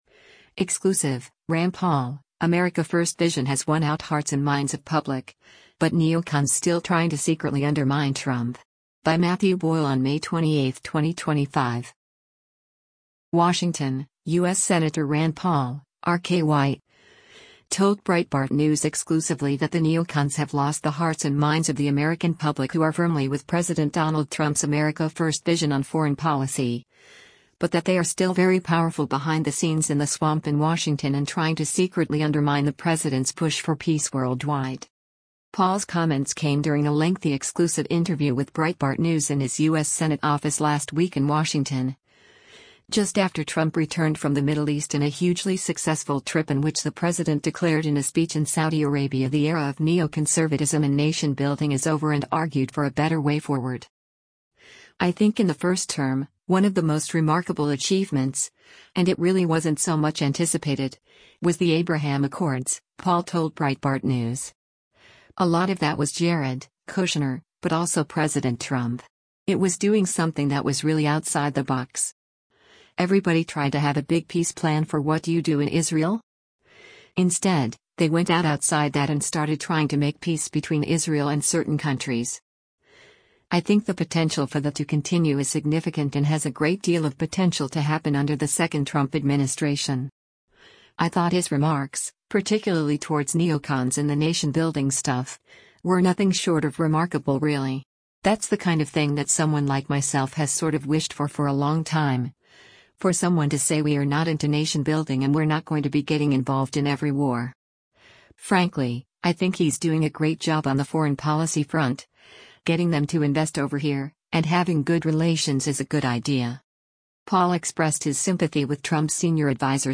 Paul’s comments came during a lengthy exclusive interview with Breitbart News in his U.S. Senate office last week in Washington, just after Trump returned from the Middle East and a hugely successful trip in which the president declared in a speech in Saudi Arabia the era of neoconservatism and nation-building is over and argued for a better way forward.